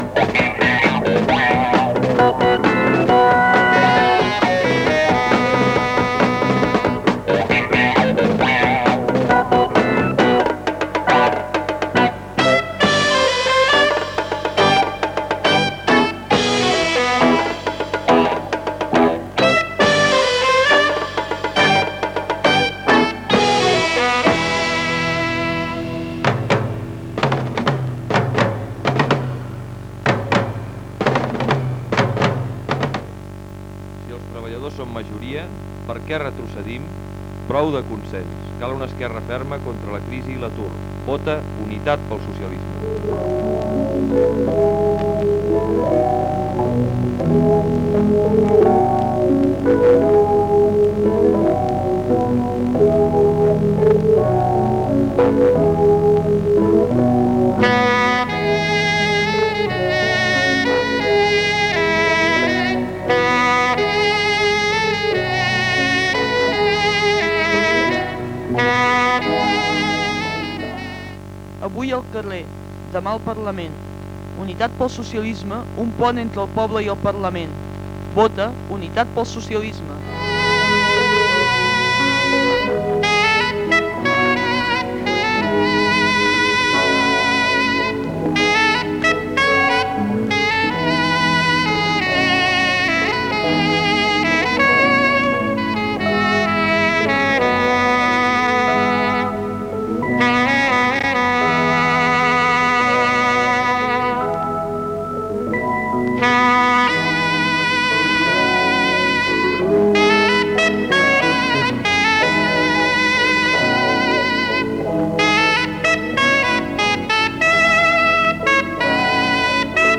Eslògans polítics, anunci de míting i cloenda d'emissió.
FM